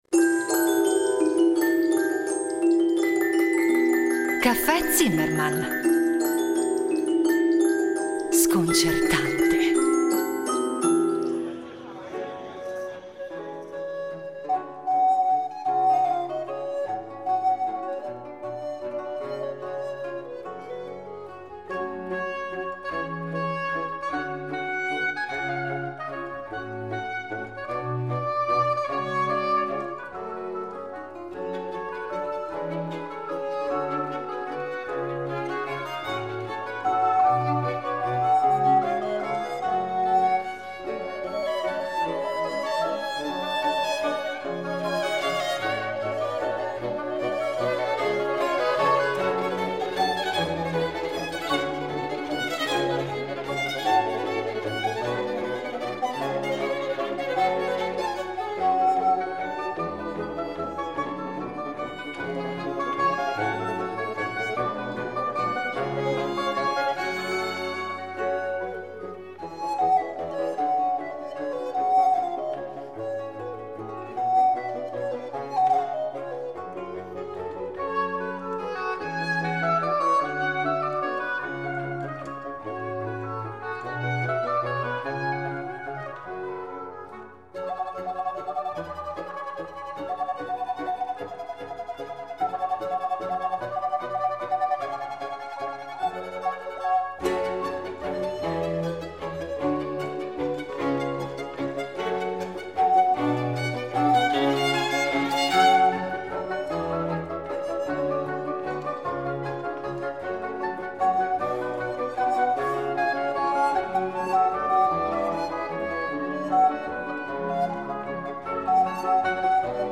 A dirigere Il Giardino Armonico dal 1989 Giovanni Antonini , tra i membri fondatori, che ai microfoni di Rete Due ne ripercorre la storia.
A guidarci le musiche della rilevante quanto varia discografia dell’ensemble (proposta in ordine cronologico).